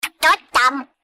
Рингтоны без слов , Голосовые